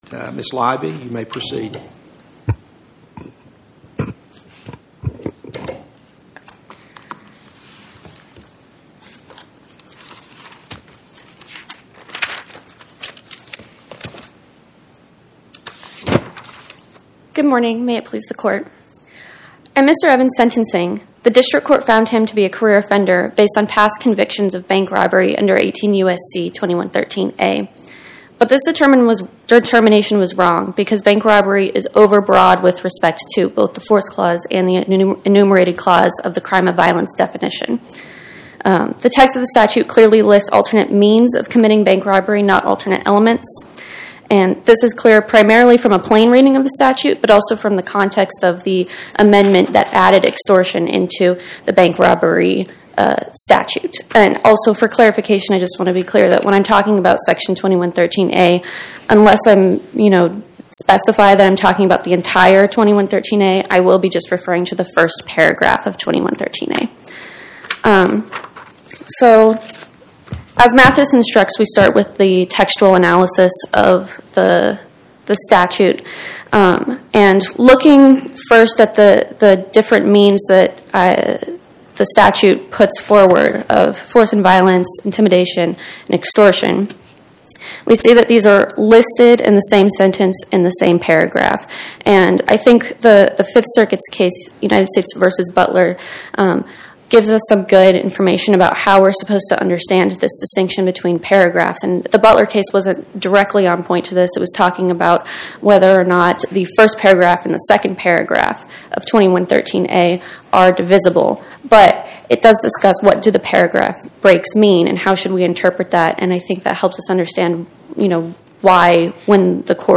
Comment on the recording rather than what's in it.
Oral argument argued before the Eighth Circuit U.S. Court of Appeals on or about 01/16/2026